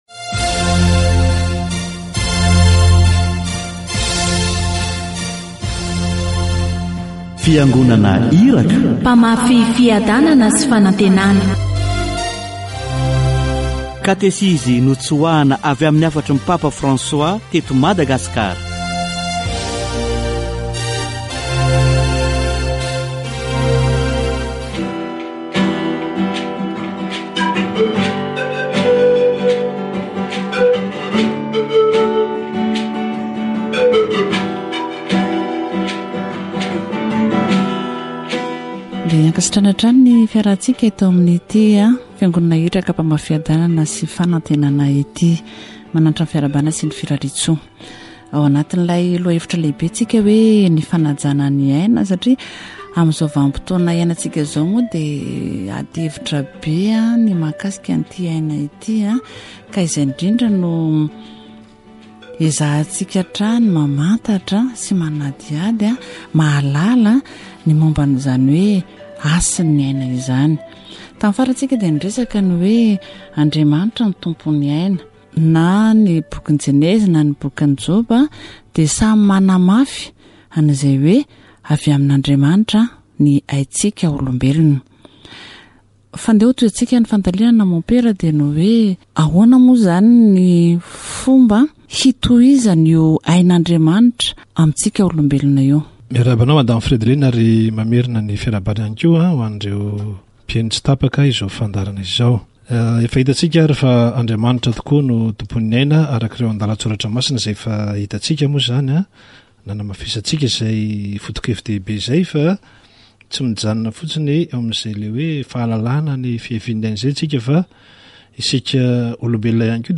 C'est une autorité donnée par Dieu à ceux qui reçoivent sa bénédiction pour continuer l'œuvre de création de la vie. Bien qu'il soit difficile pour les gens de marcher sur cette terre, ils font tout ce qui est en leur pouvoir pour préserver la vie. Catéchèse sur l'Ascension de Jésus